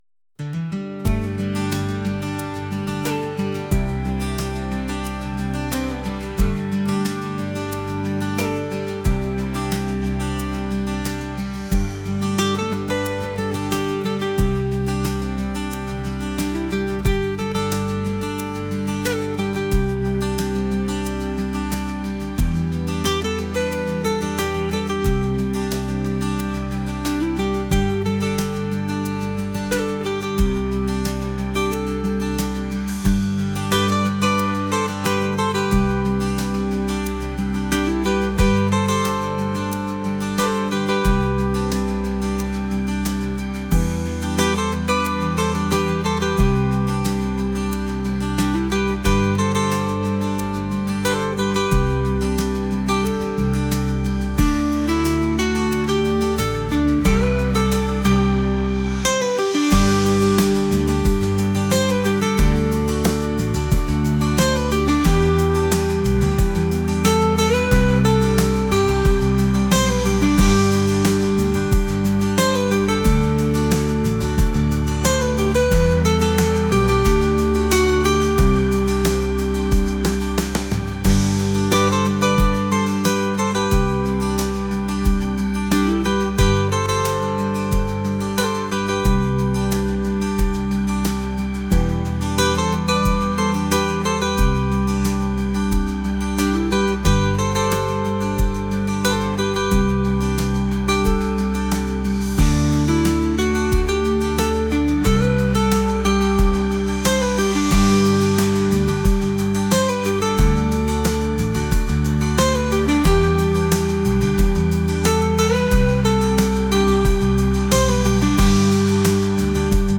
acoustic | pop | ambient